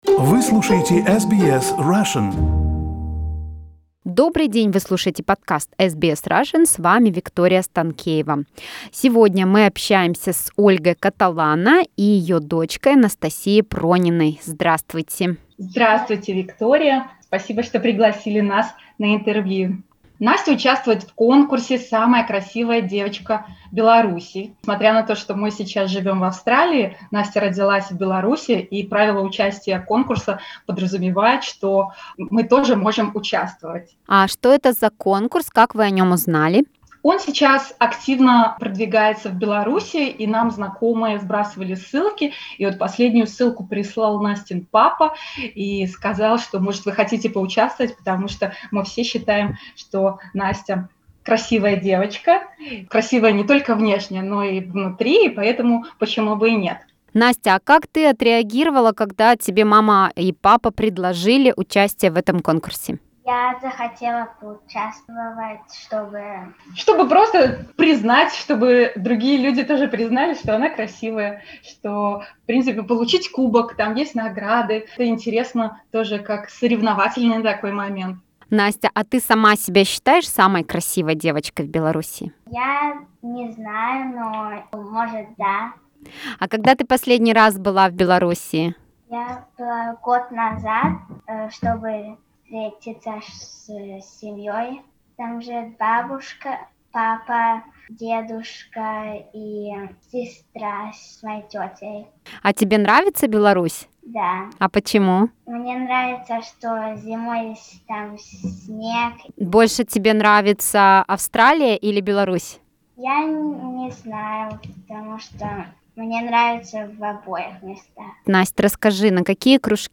The interview